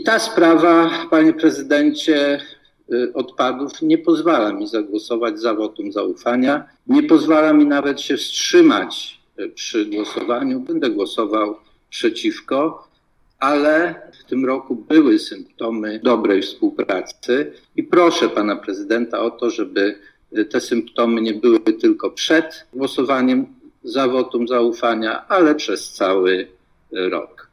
– To właśnie sprawa odpadów przesądziła, że nie będę głosował za udzieleniem wotum zaufania – mówił na sesji Szelążek.